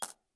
coin_coin_4.ogg